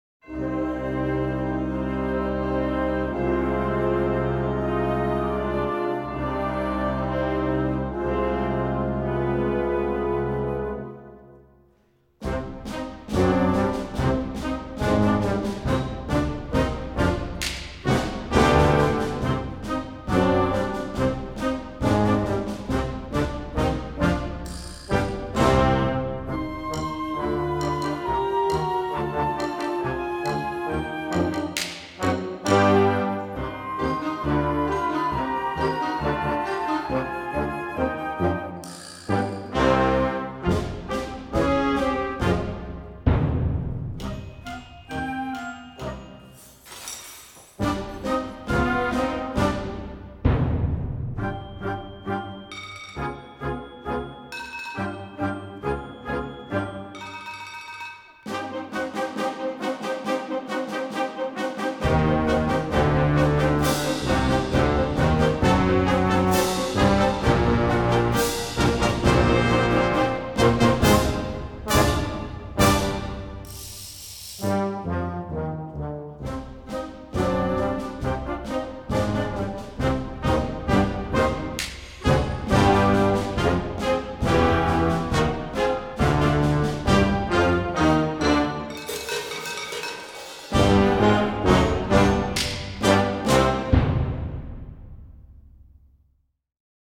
Gattung: Konzertwerk für Jugendblasorchester
Besetzung: Blasorchester
Einfach spannend, aber auch herrlich schaurig!